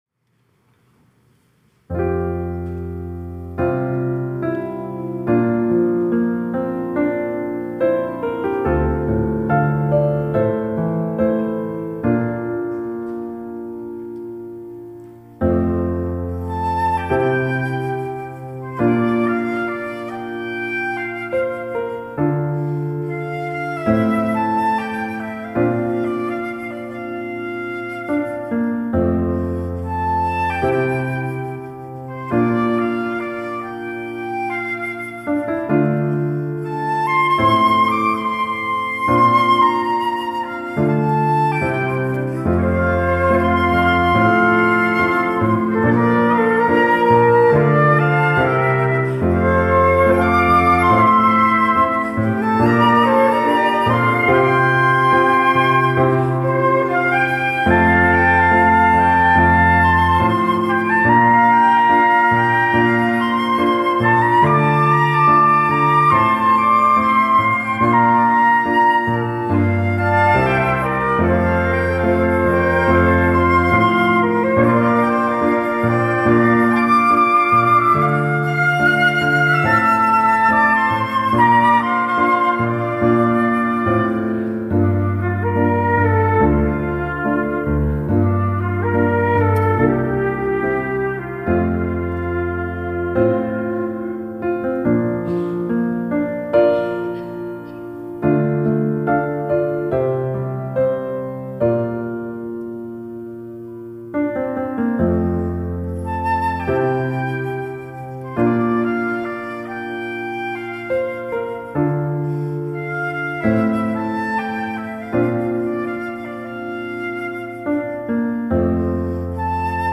특송과 특주 - 밀알
플룻 앙상블